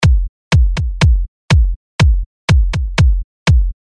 120-125bpm
Mixed drum machine samples
For our kick drum, we’re layering a snappy sound with a deeper hit.
The kick plays a four-to-the-floor pattern with added ghost notes to give a bouncy groove.